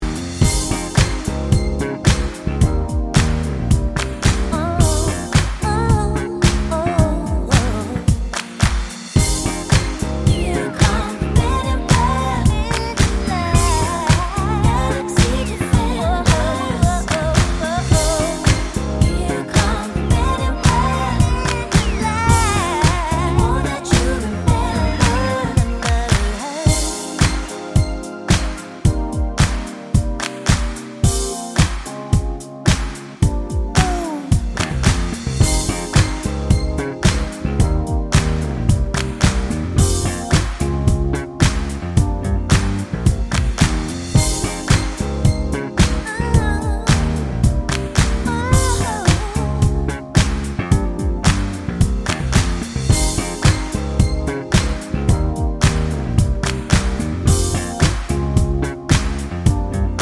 R'n'B / Hip Hop